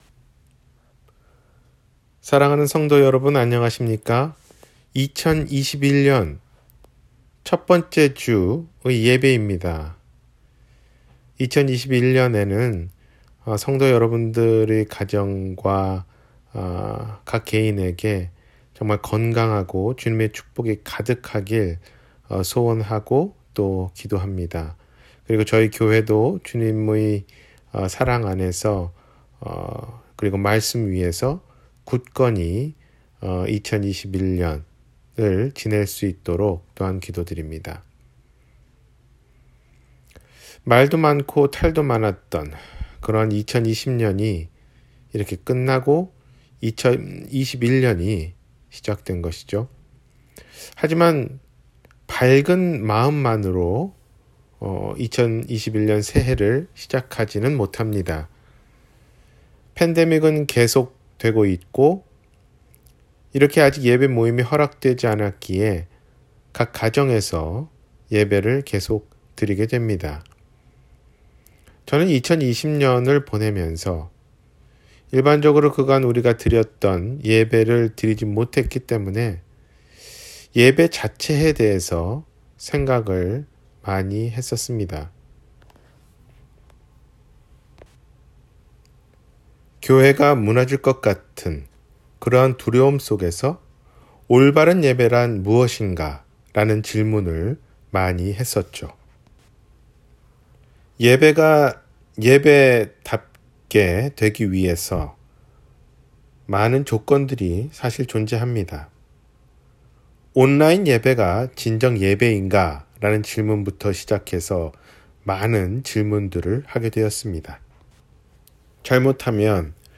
2021년 우리가 할 일 – 주일설교